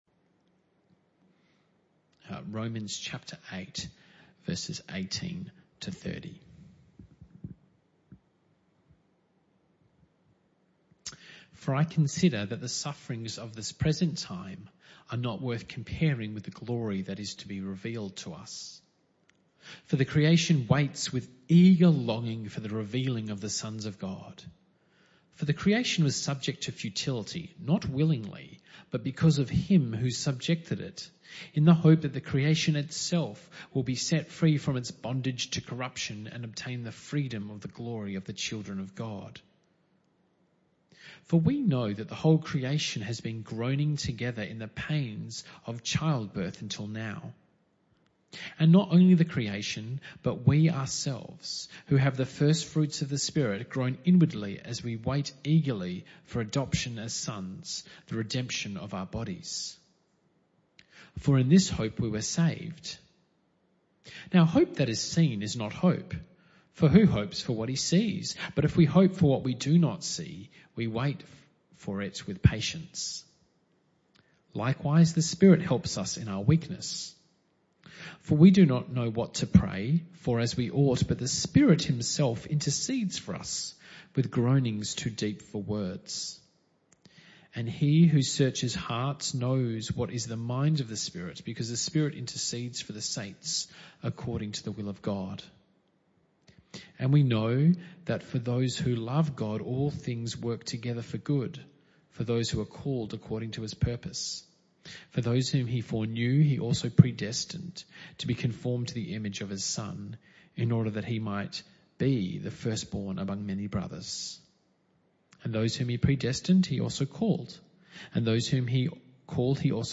Romans 8:18-30 Service Type: Evening Service Bible Text